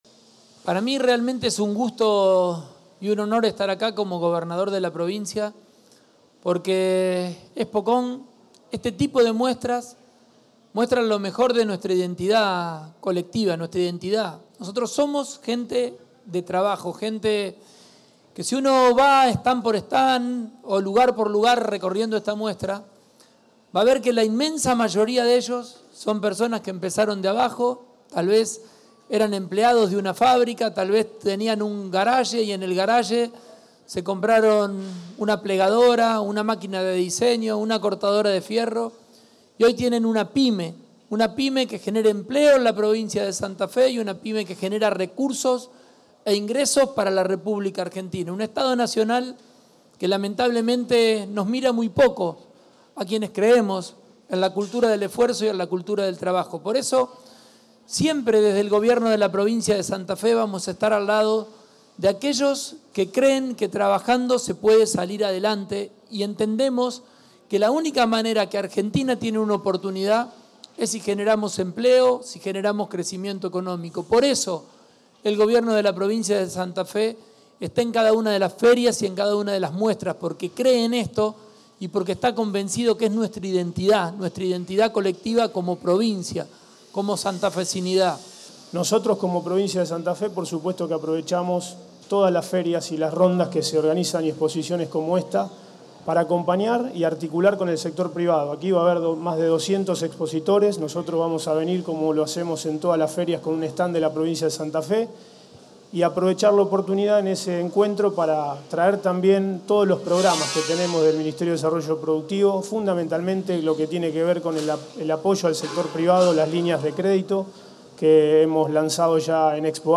El gobernador Maximiliano Pullaro participó este lunes de la presentación de la Expocon 2025, Exposición de la Construcción, que se realizará del 3 al 6 de abril, en la ciudad de Funes, y tiene como objetivo visibilizar los avances y oportunidades del sector, así como promover el desarrollo económico y la inversión en nuestra región.